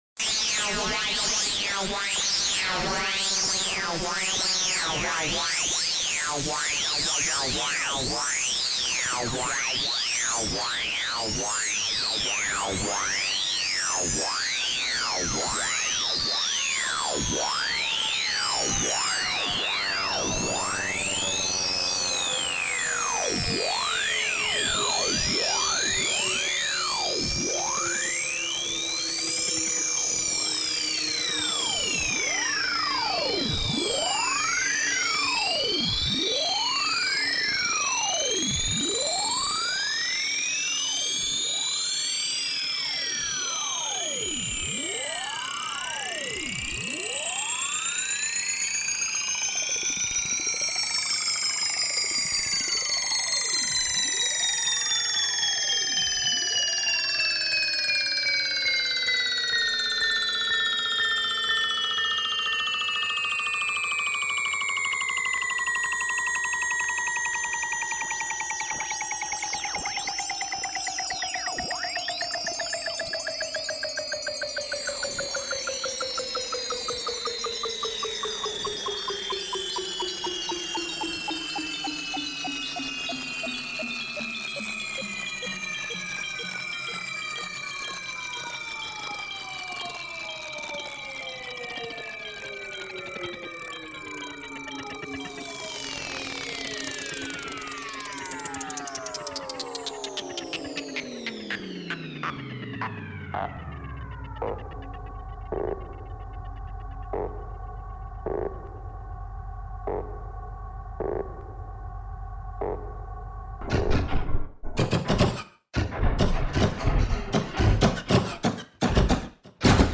Mastering @ Le Centre De La Bombe